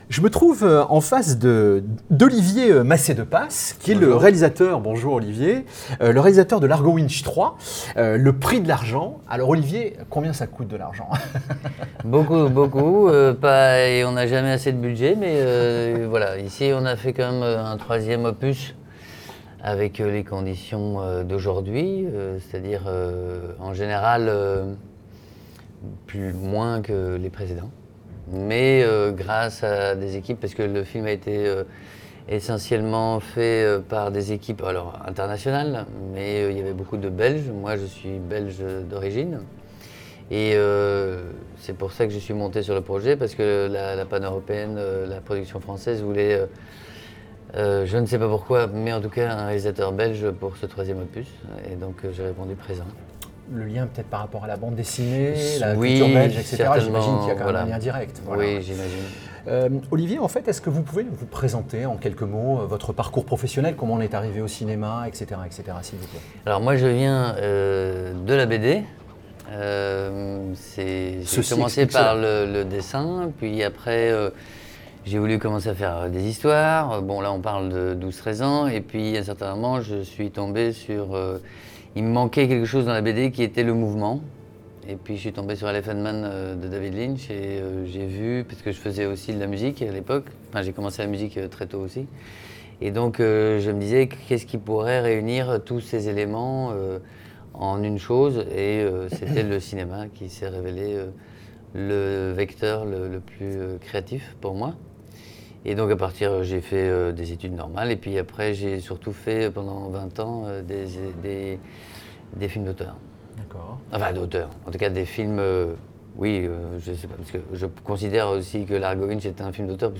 Largo Winch, une suite est prête. Interview